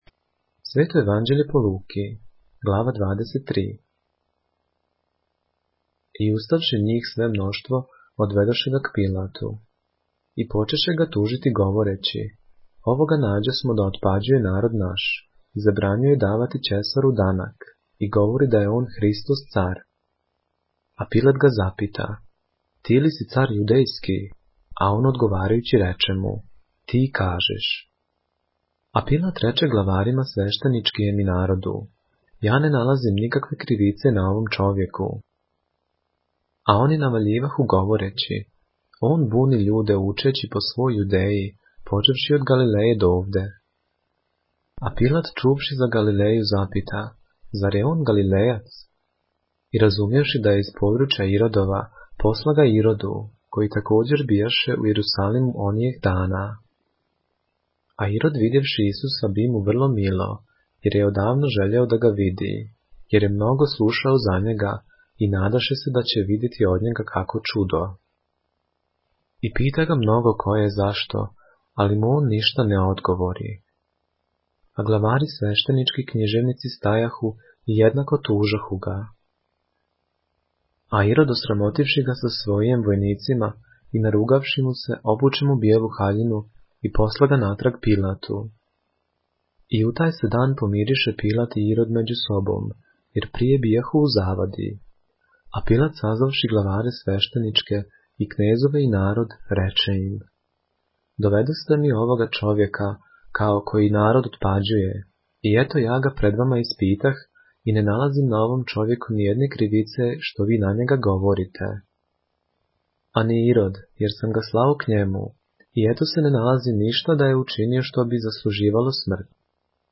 поглавље српске Библије - са аудио нарације - Luke, chapter 23 of the Holy Bible in the Serbian language